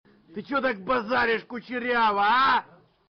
голосовые
грубые